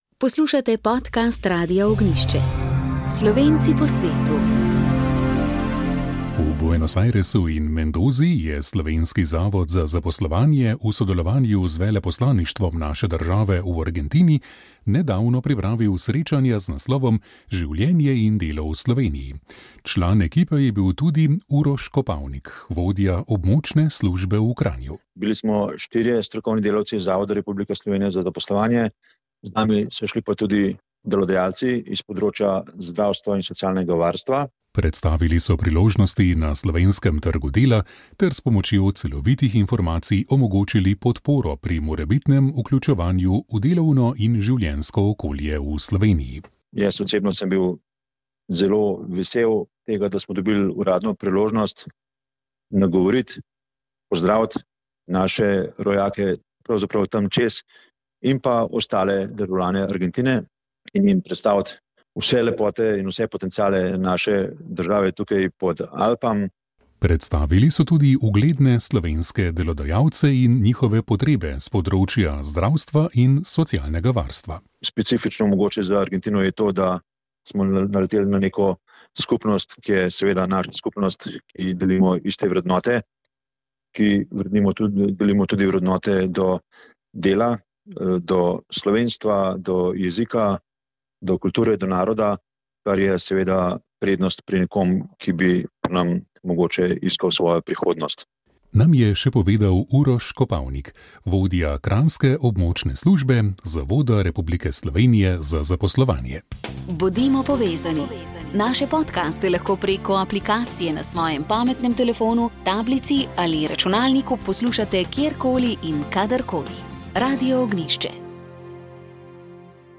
V dunajski galeriji Spodnji Belvedere je na ogled razstava Svet v barvah: Slovensko slikarstvo 1848-1918. Razstava, ki jo je galerija Belvedere pripravila skupaj z Narodno galerijo Slovenije, združuje 132 del slovenskih in avstrijskih umetnikov. Prisluhnite pogovoru